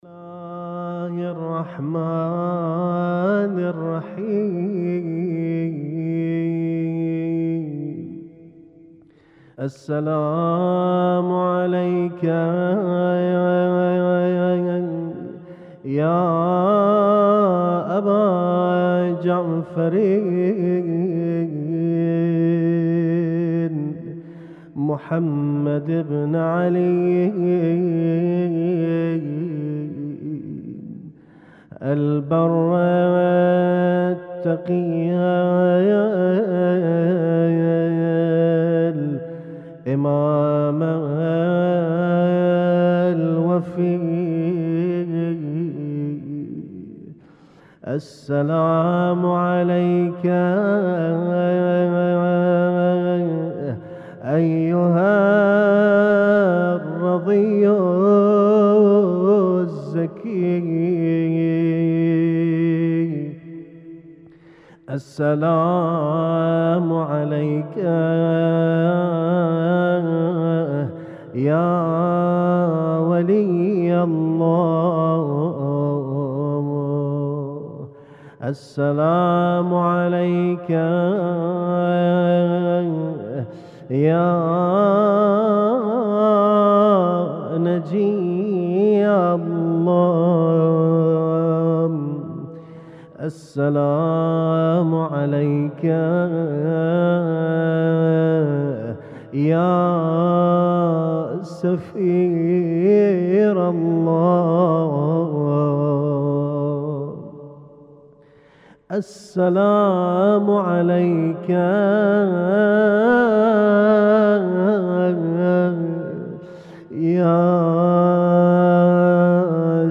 Husainyt Alnoor Rumaithiya Kuwait
اسم التصنيف: المـكتبة الصــوتيه >> الزيارات >> الزيارات الخاصة